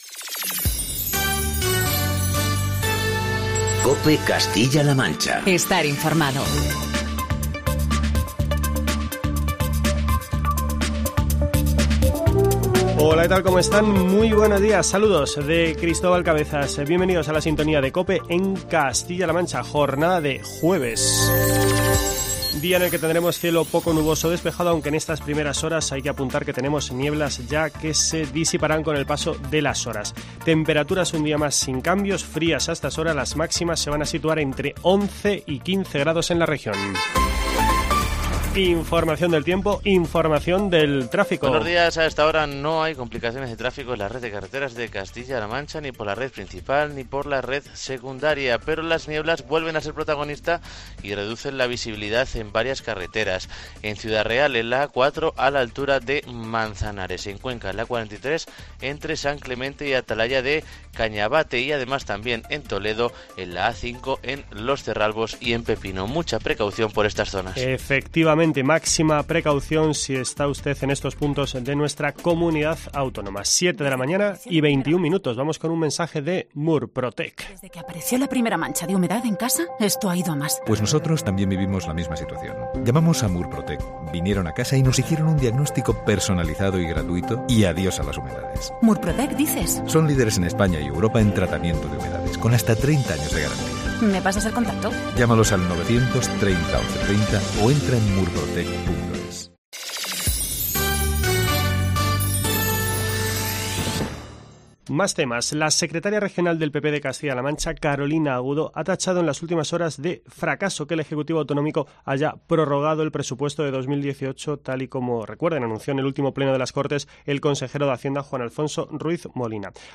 Informativos matinales de COPE Castilla-La Mancha.